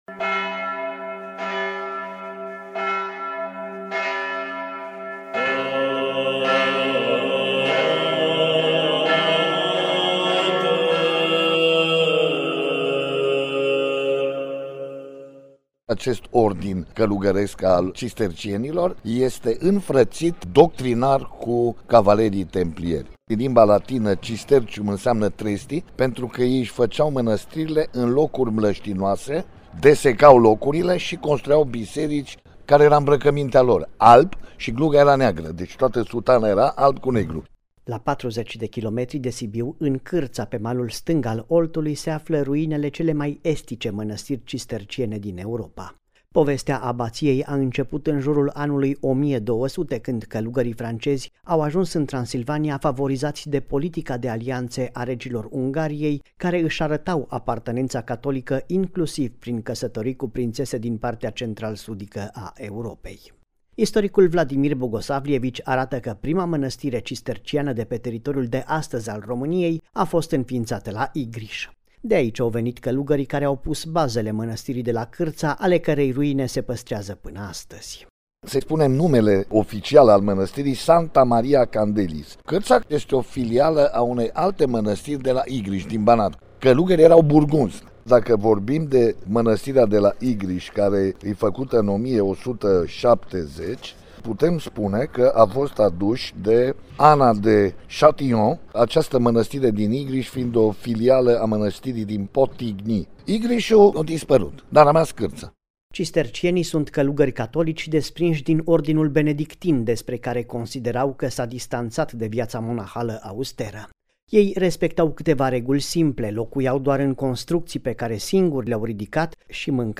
reportaj-manastirea-cisterciana-de-la-Carta.mp3